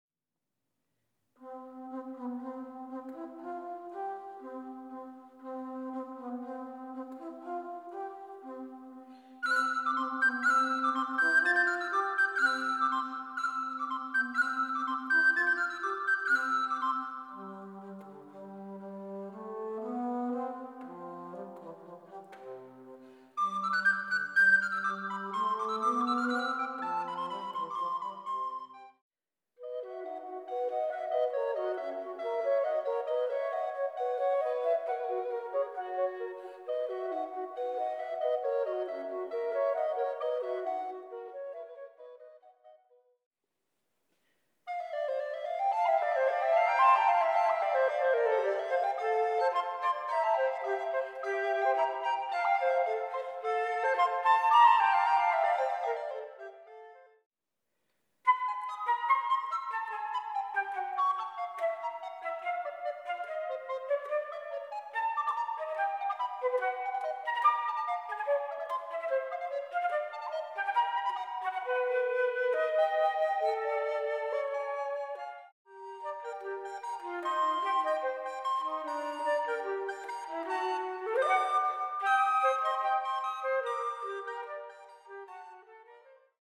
Von der Renaissance über den Barock bis zur Moderne entführen ihre Klänge zusammen mit Geschichten ins Reich der Sehnsucht, Fantasie und Poesie.
Flutestories.mp3